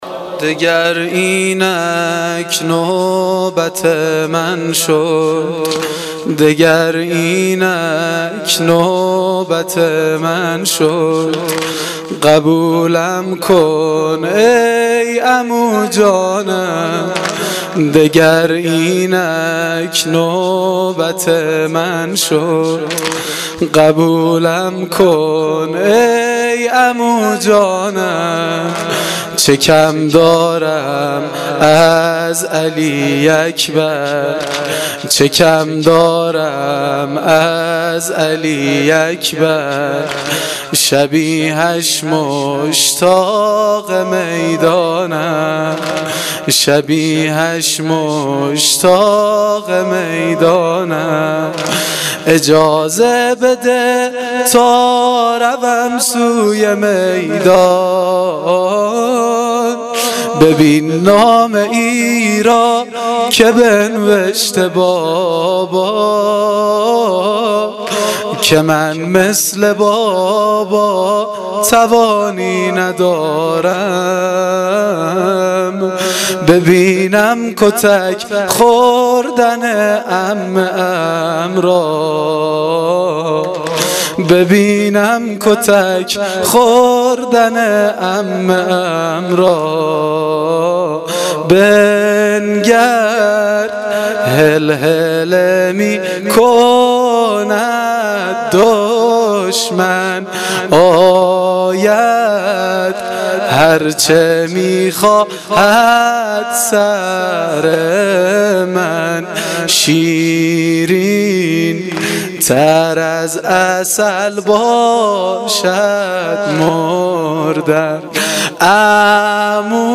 واحد سنگین شب ششم محرم